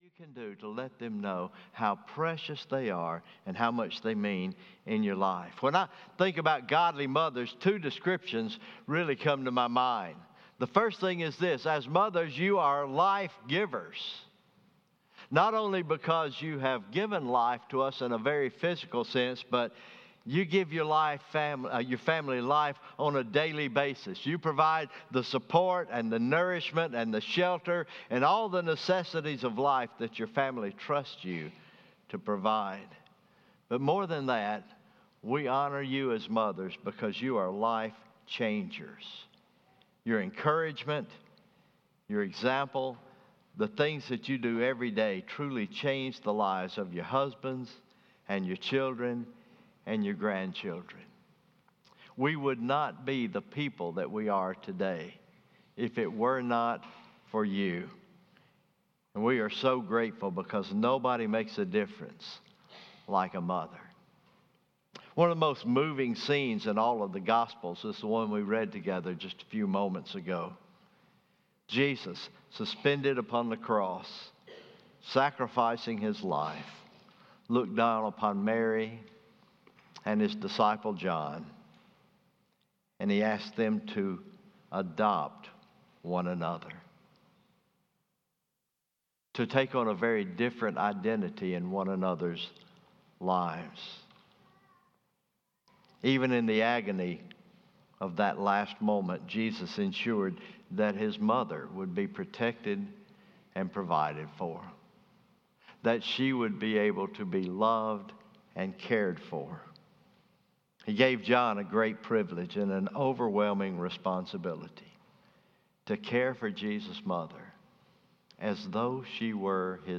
May 12, 2024 Morning Worship, Mother’s Day